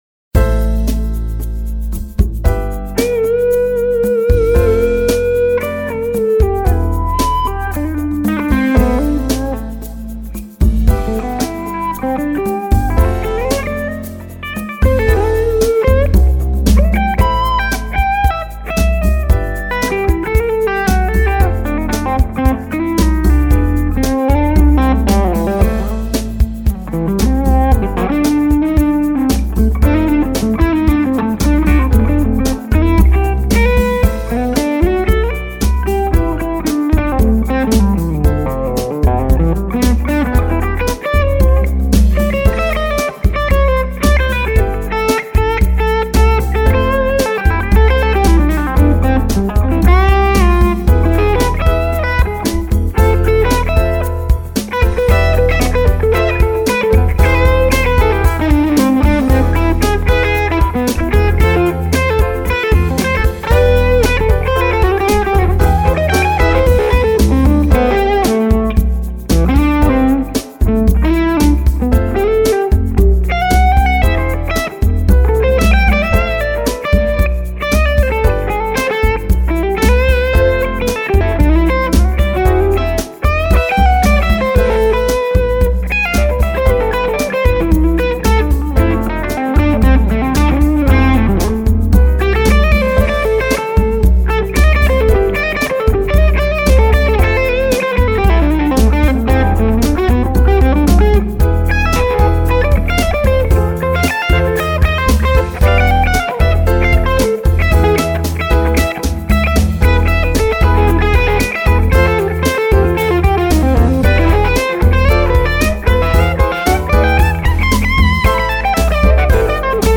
Bludo is 6L6 high plate Skyline non HRM w/ several tweaks
Both running through the external Loopalator with 200pf cables.
Same mics, positioning, cab (2X12 G1265), mic pres, etc.... Only dleay and verb added in mix. No EQ. No altering.
I think I prefer the Bludo, sounds more dynamic and responds/compliments to your playing nicely.
A little more bite, more umph, more *something* that my ears really like.